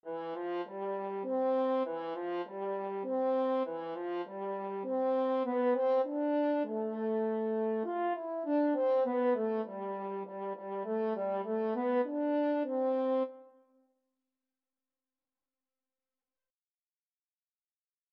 Moderato
3/4 (View more 3/4 Music)
French Horn  (View more Beginners French Horn Music)